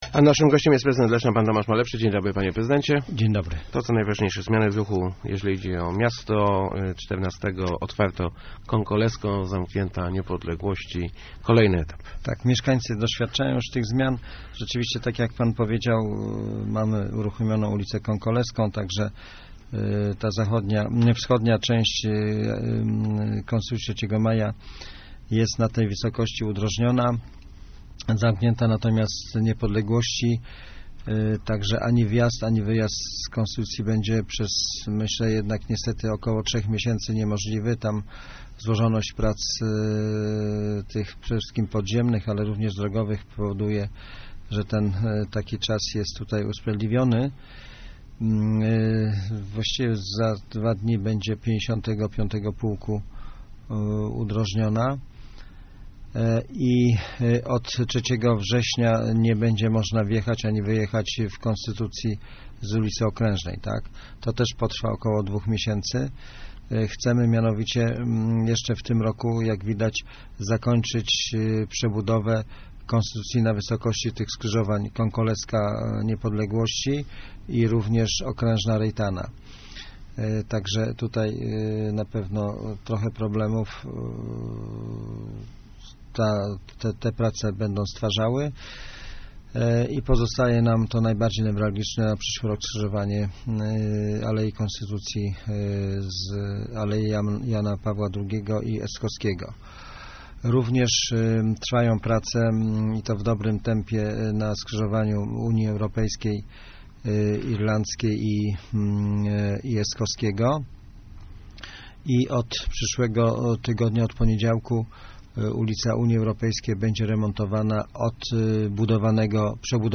Gościem Kwadransa Samorządowego był prezydent Tomasz Malepszy ...